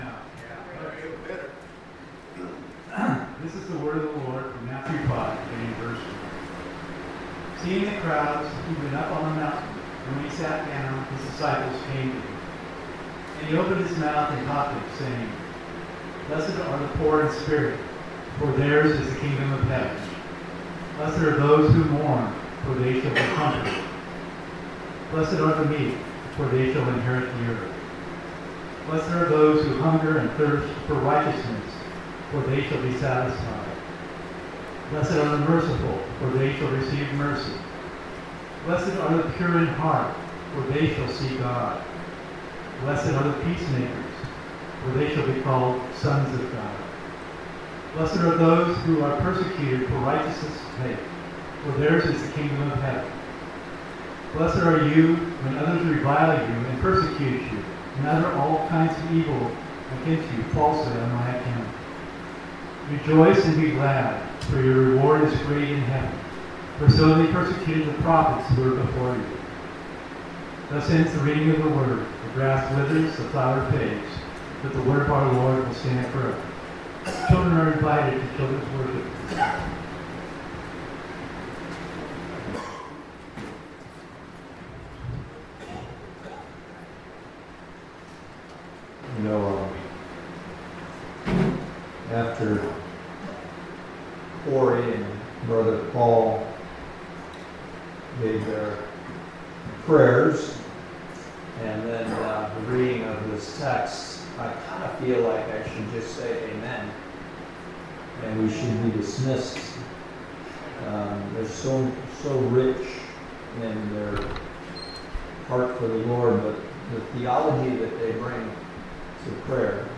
Passage: Matthew 5:8 Service Type: Sunday Morning